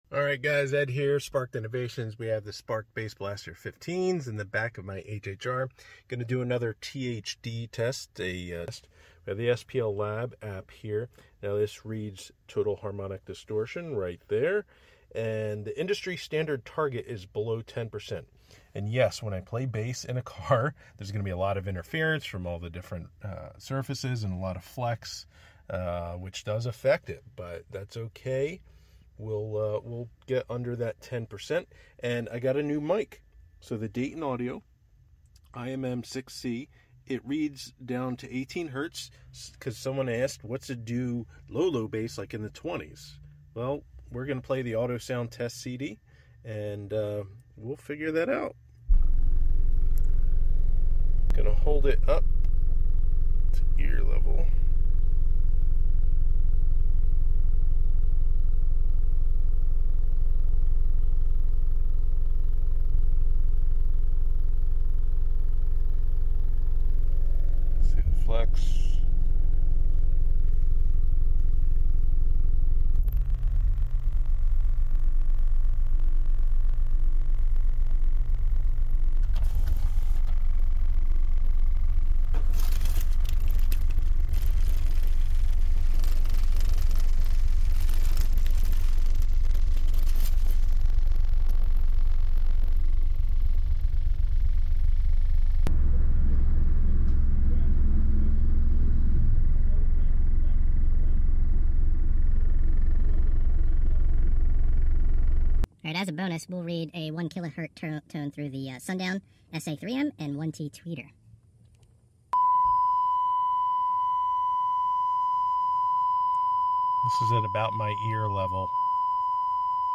Clean Bass from the Sparkes woofers. THD test.